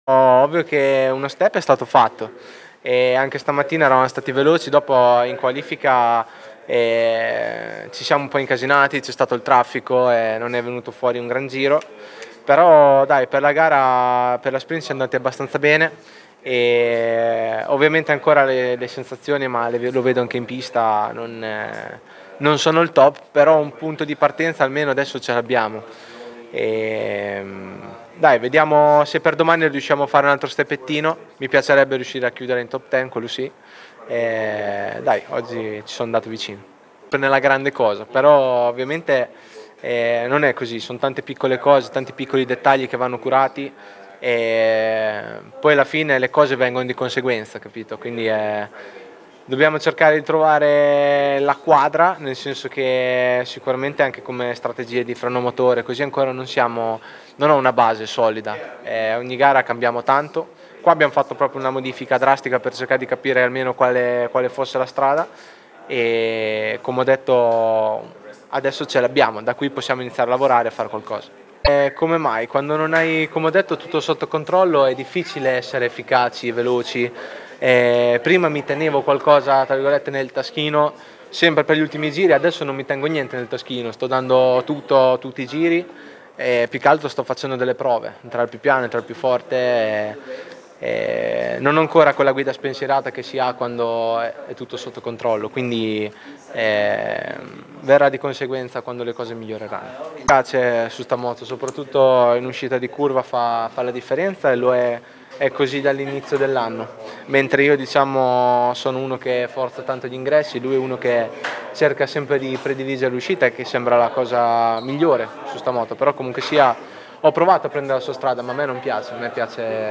Enea Bastianini al microfono